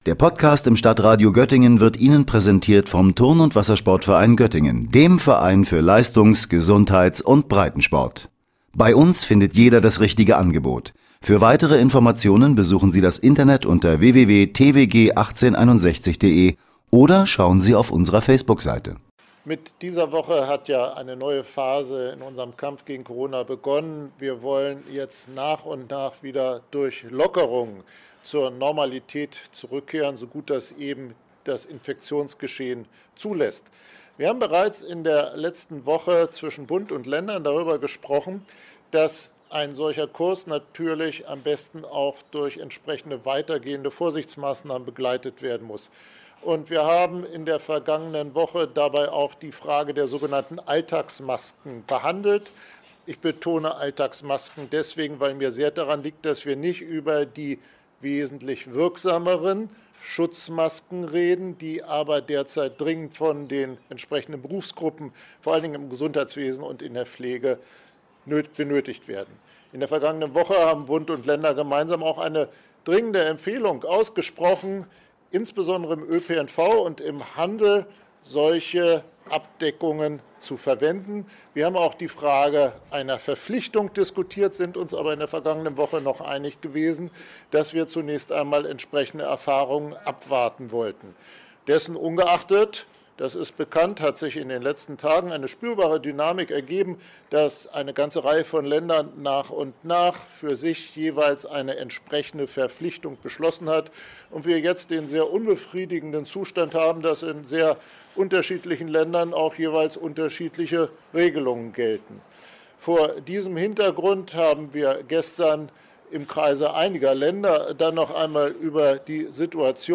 Heute Nachmittag trat Weil vor die Presse und erläuterte die Entscheidung.